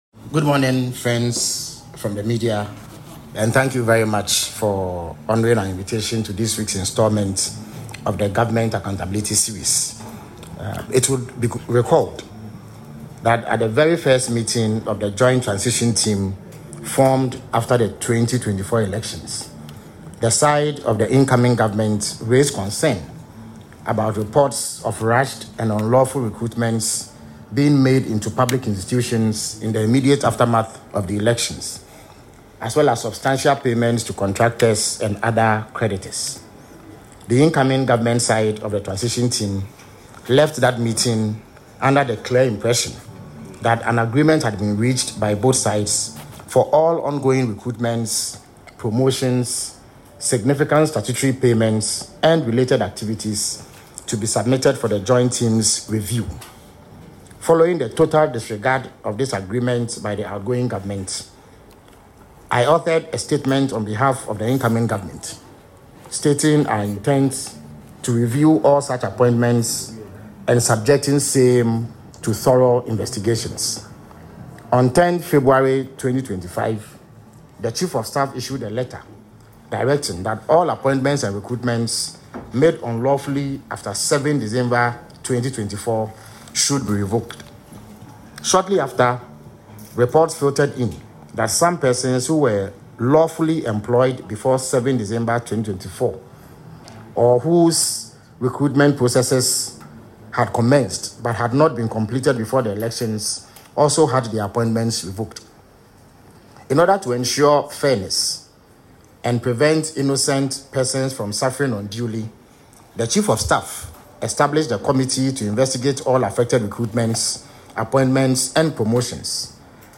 The announcement was made during this week’s Government Accountability Series, where the Minister of Government Communications Felix Kwakye Ofosu detailed the outcome of a committee set up to investigate recruitments made around the December 7, 2024 elections.